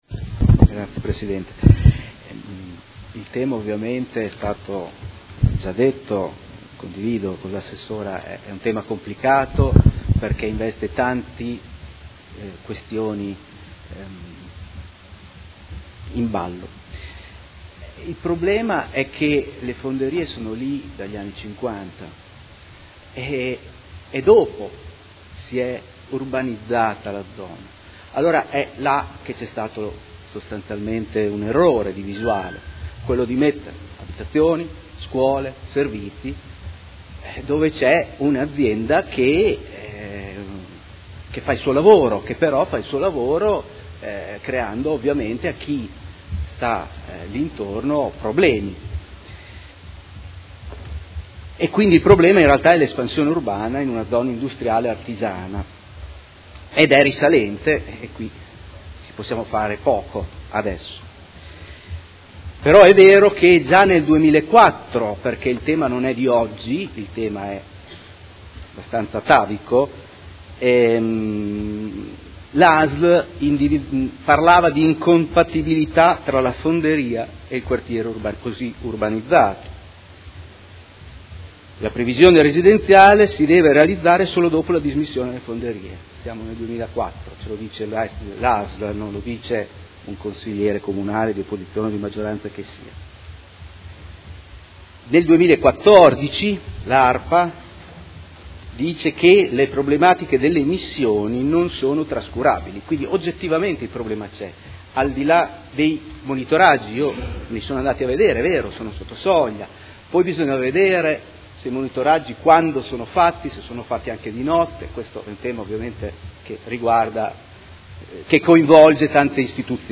Seduta del 7 novembre 2019.